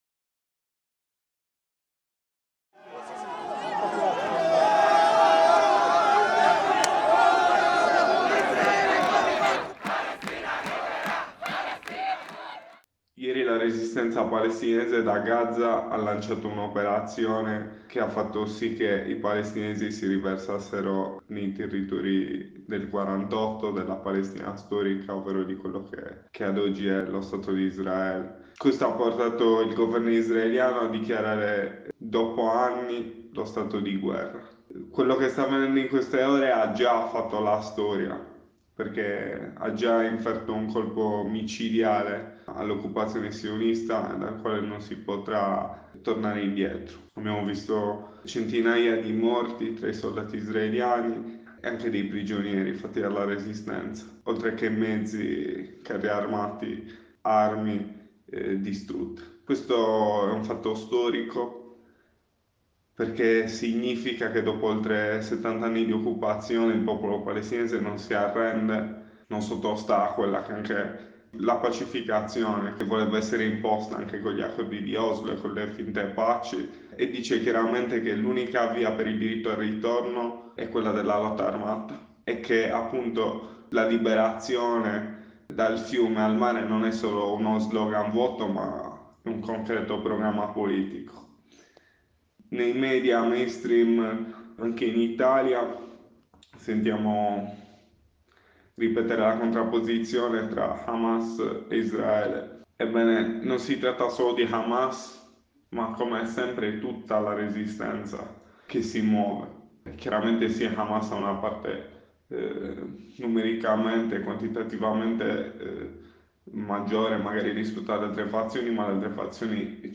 Corrispondenza da Parigi